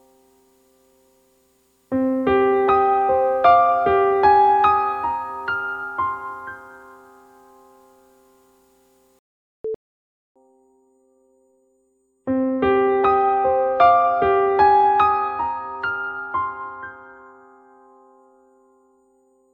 I tried using the Noise Removal function, but it distorted the piano sound.
The only fault I can hear is the level of noise (hiss) is quite high,
Noise reduction can reduce it, (see attached mp3), but like you’ve found it will distort the sound to some degree.